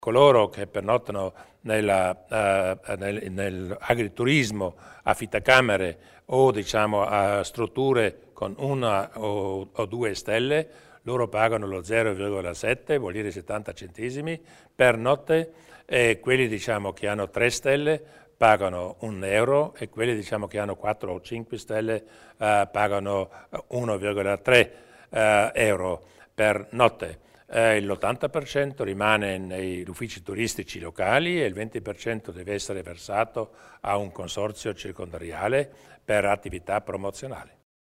Il Presidente Durnwalder illustra le novità in tema di finanziamento del turismo